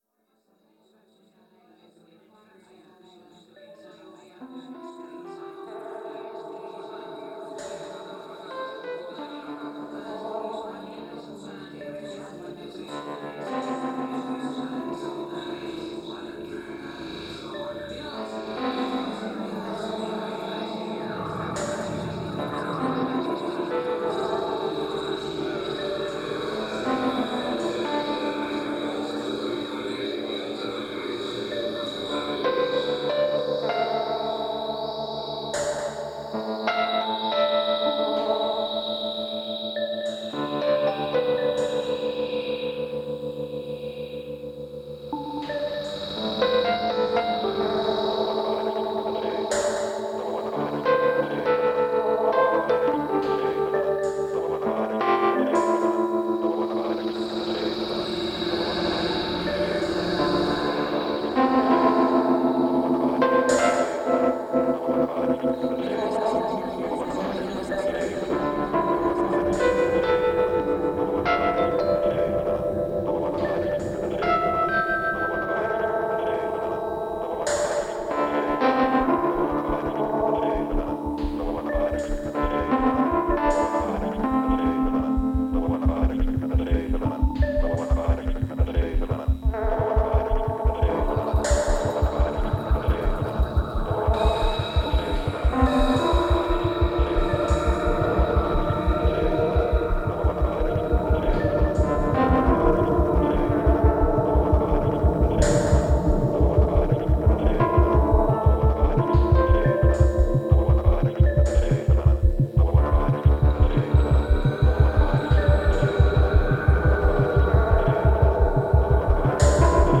live recordings during 03-04 Sahkomakkonen